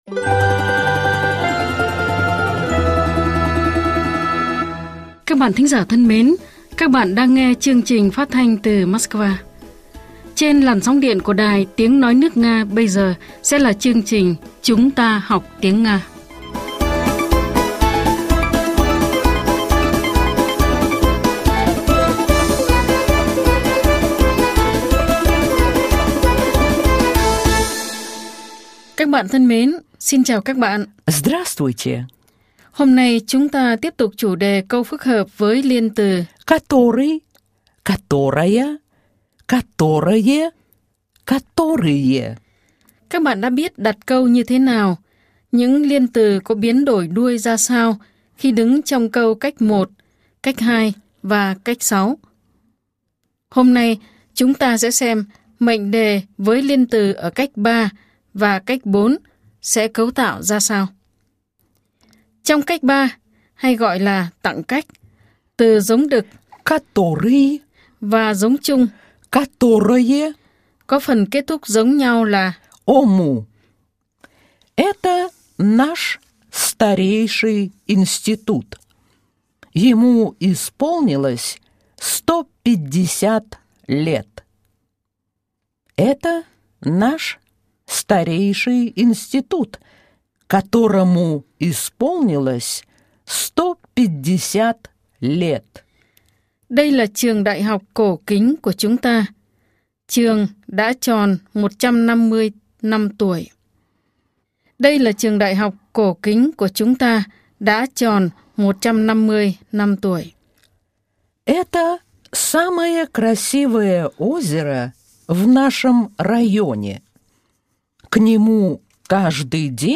Bài 52 – Bài giảng tiếng Nga
Nguồn: Chuyên mục “Chúng ta học tiếng Nga” đài phát thanh  Sputnik